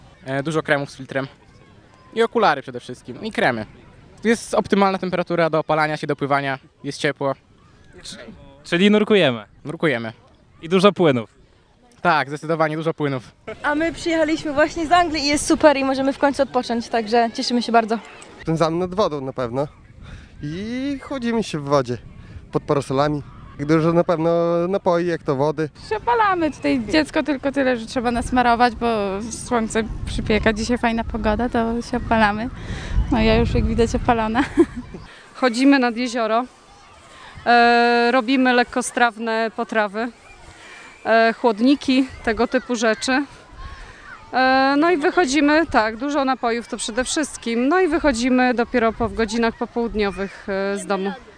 Nasz reporter z mikrofonem odwiedził dziś Plażę Miejską w Ełku przy ul. Parkowej.
sonda-upały-1.mp3